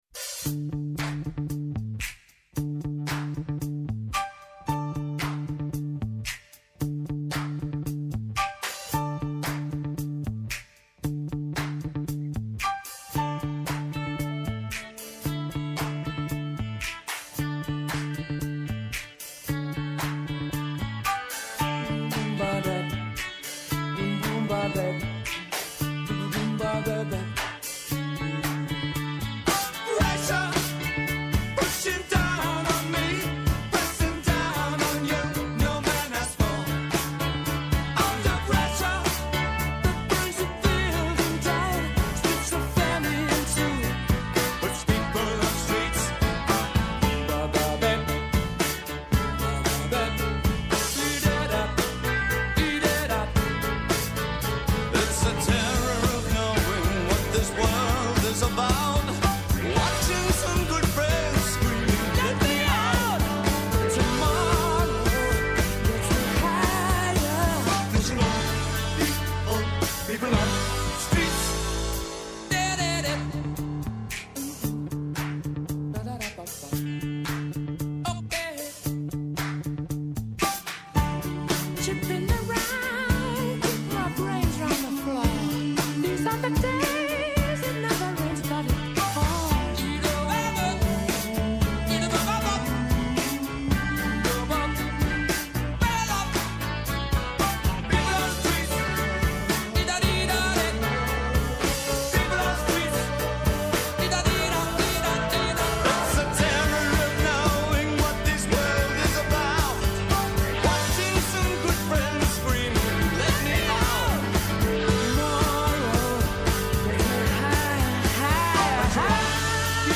Καλεσμένος στο στούντιο της εκπομπής ο Δημήτρης Παπαστεργίου, υπουργός Ψηφιακής Διακυβέρνησης